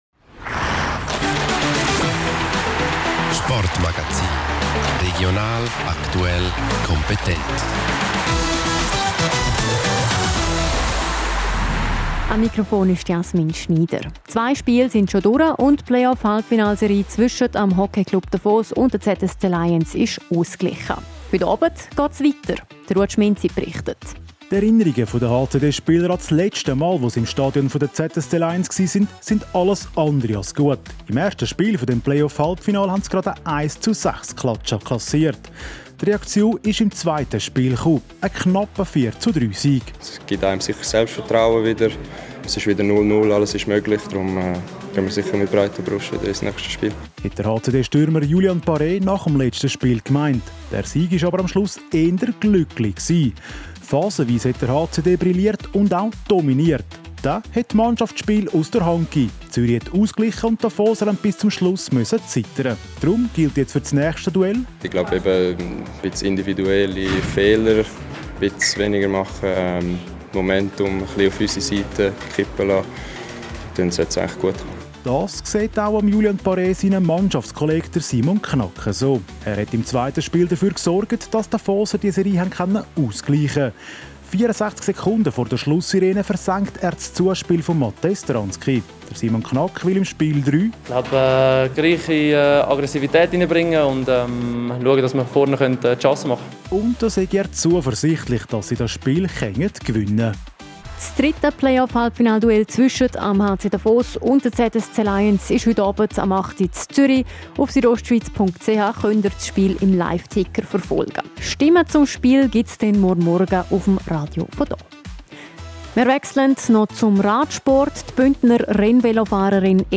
Sport Magazin
1740 Sportmagazin (mit INTROOUTRO).MP3